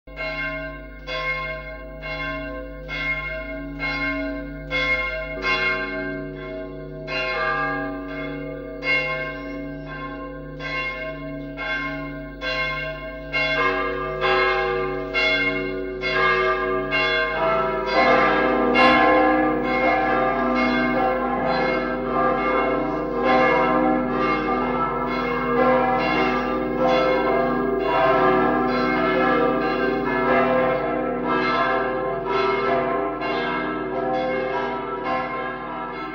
– Glockenläuten aus Zeiden (288 Downloads )